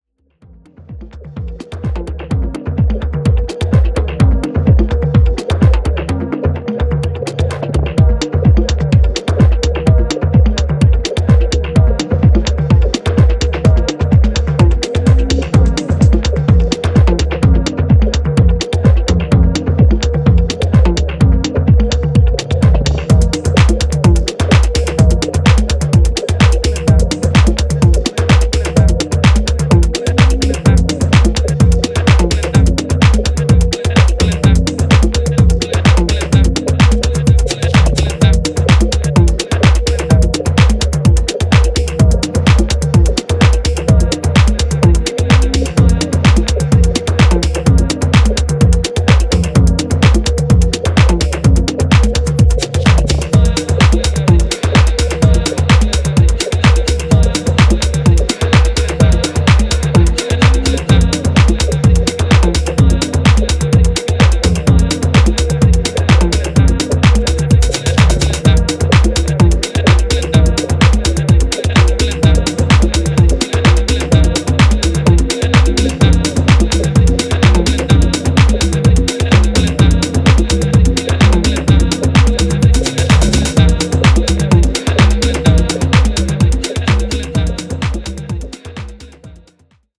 フリップの2曲は、一段とテンションを落としたミニマル・ハウス路線の仕上がり。